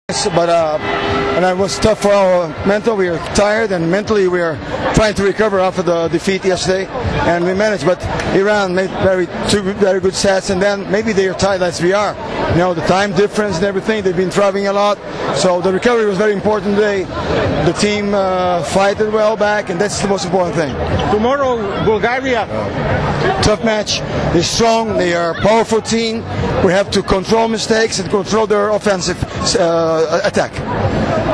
IZJAVA BERNARDA REZENDEA
b2rezende.WMA